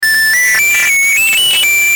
Old Spice Earrape Download